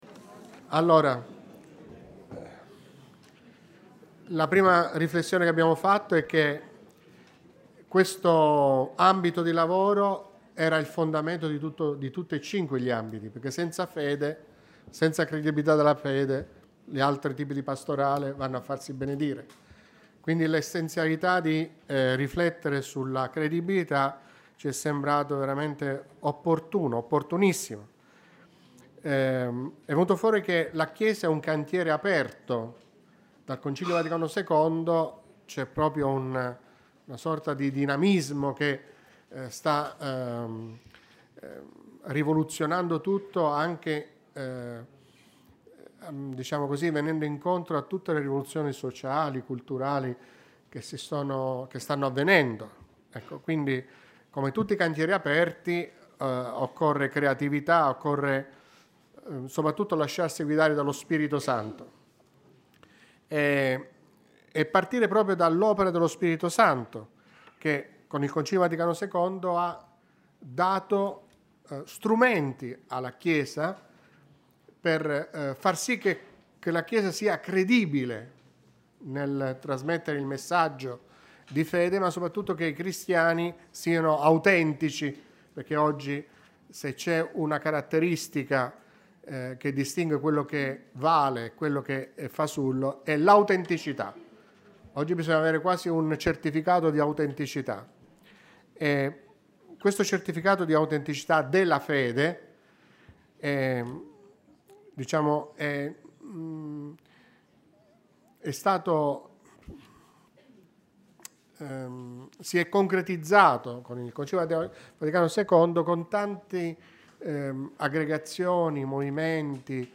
16 settembre 2023, Santuario di San Gabriele dell’Addolorata